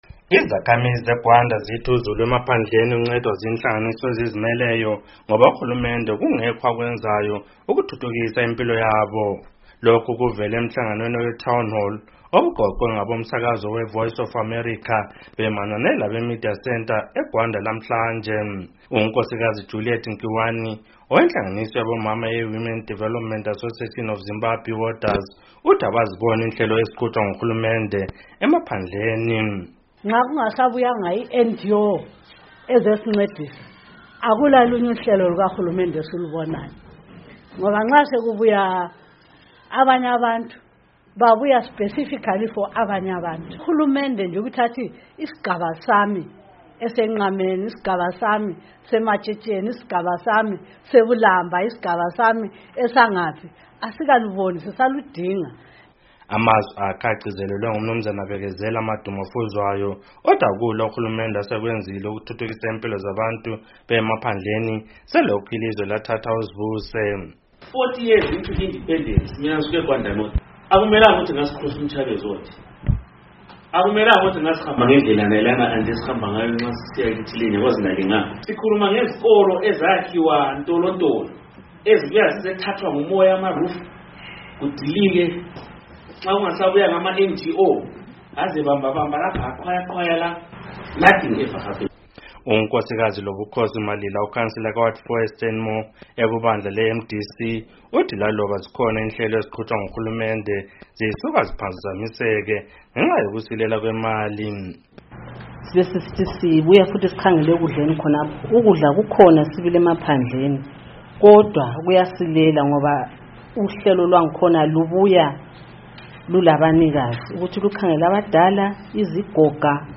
Lokhu kuvele emhlanganweni oweTown Hall obuqoqwe ngabomsakazo owe VOA Studio 7 bemanyane labeMedia Centre, eGwanda lamhlanje.